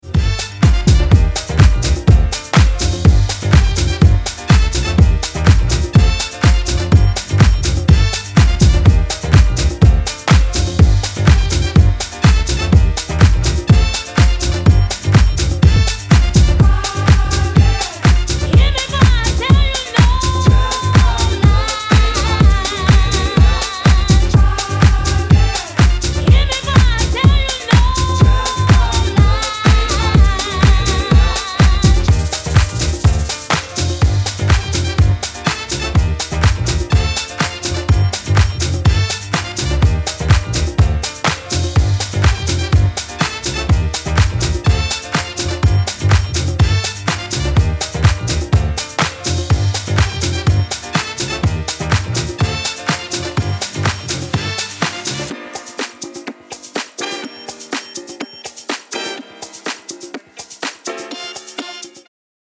各曲、煽りに煽るフィルター使いとマッシヴなボトムの威力が光ります。